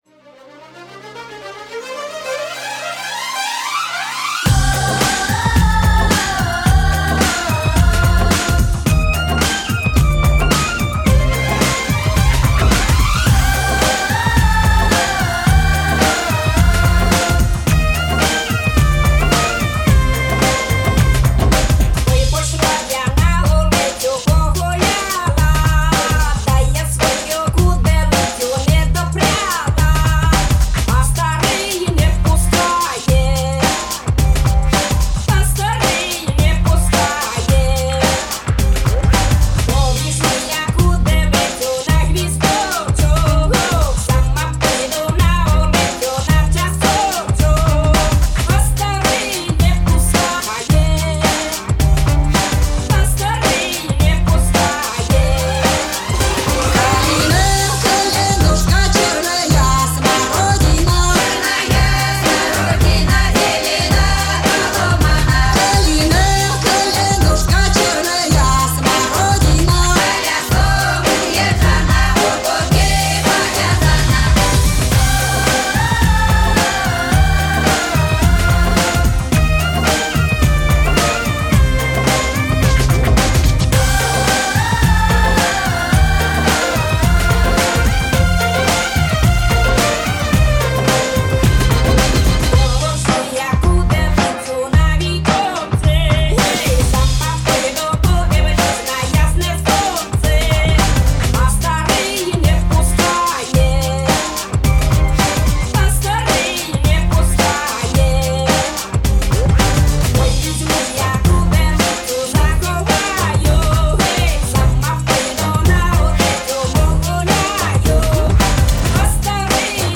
электронная поп-группа, работает в этническом стиле.
Genre: Folk